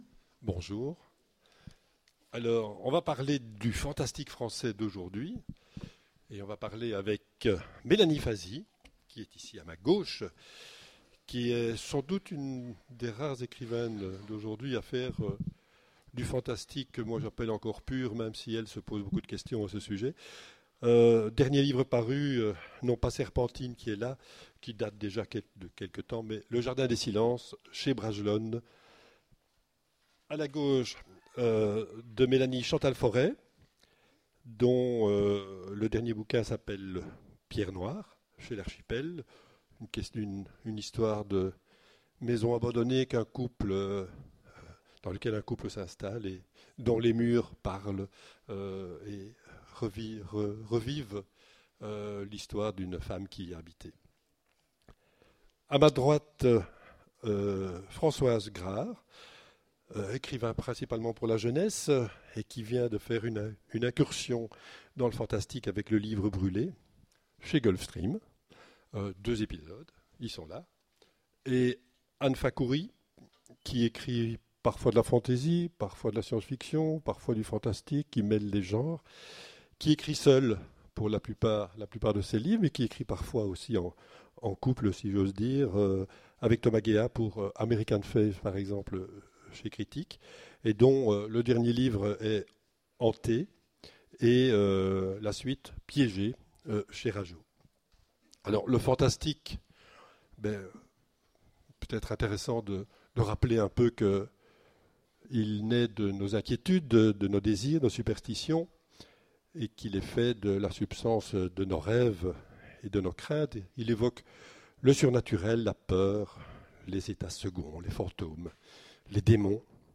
Imaginales 2015 : Conférence Le fantastique français...